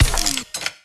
rr3_sfx_repair.wav